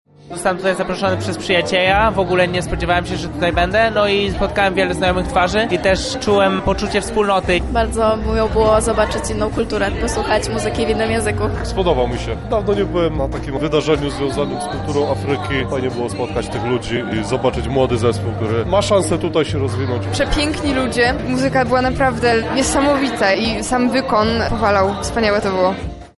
O wrażenia związane z koncertem zapytaliśmy słuchaczy.